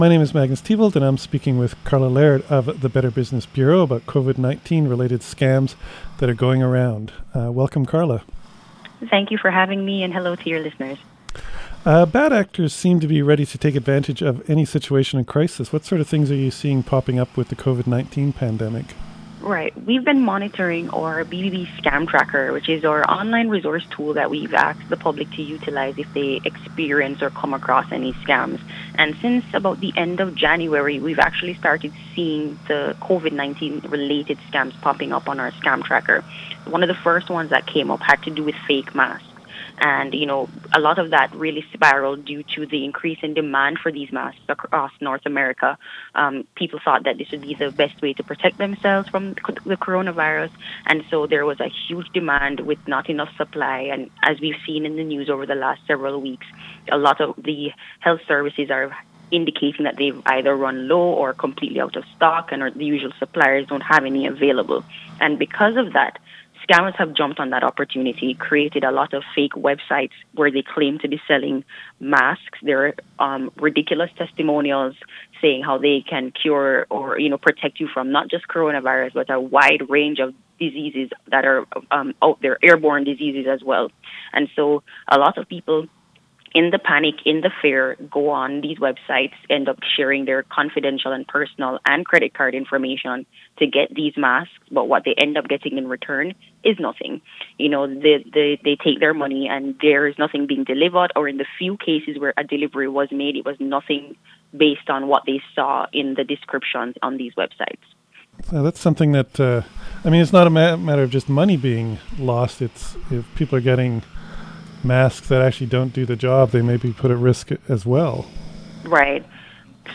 Better_Business_Bureau_Interview_COVID-19_Scams.mp3